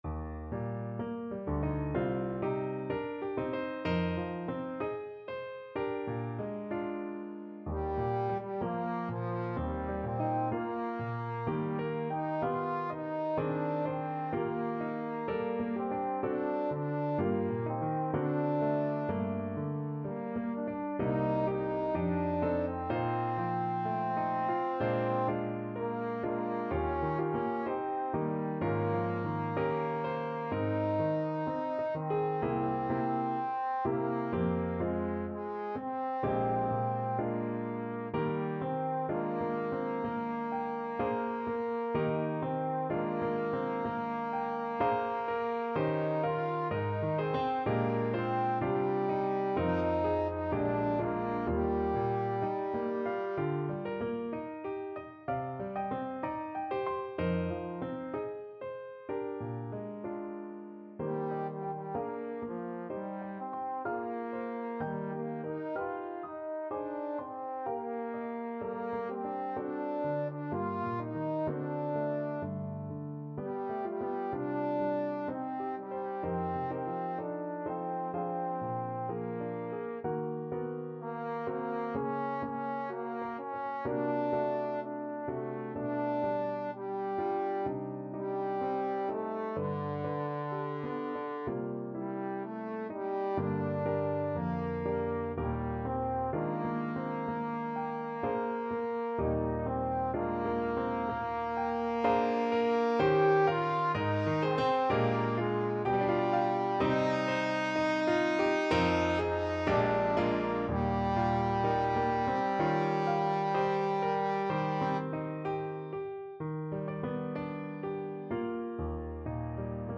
Trombone
Eb major (Sounding Pitch) (View more Eb major Music for Trombone )
4/4 (View more 4/4 Music)
Andante moderato poco con moto =63) (View more music marked Andante Moderato)
D4-G5
Classical (View more Classical Trombone Music)